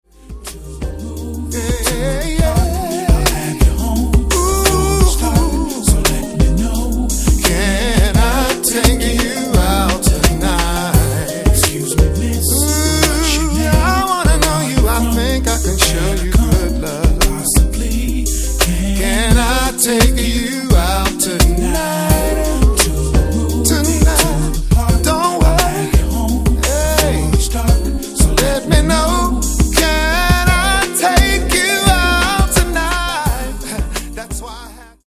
Genere:   RnB | Soul | Dance